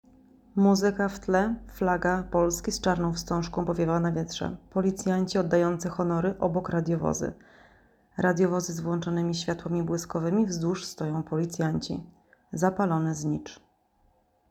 Nagranie audio audiodeskrycja filmu.m4a